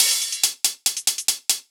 Index of /musicradar/ultimate-hihat-samples/140bpm
UHH_ElectroHatB_140-03.wav